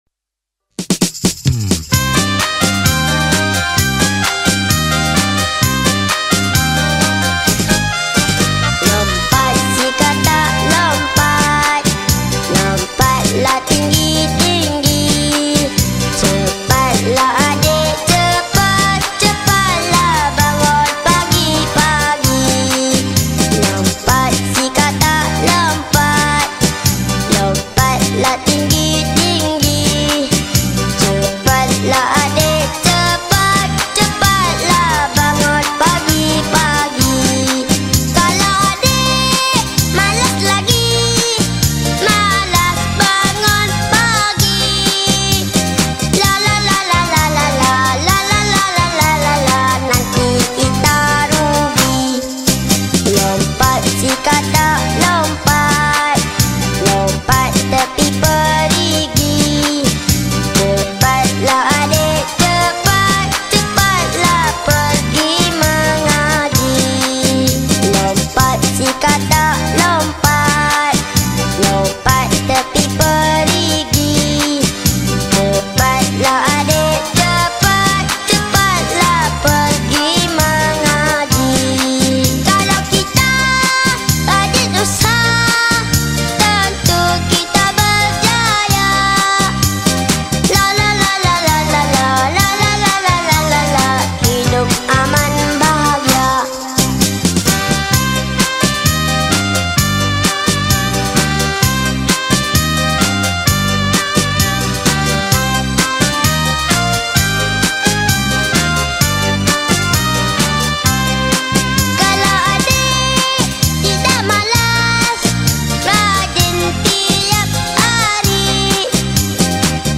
Malaysia Tradisional Children Song